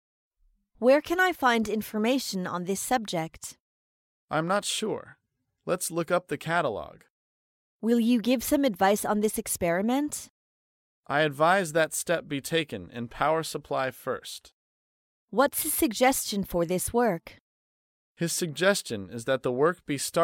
高频英语口语对话 第144期:工作建议(2) 听力文件下载—在线英语听力室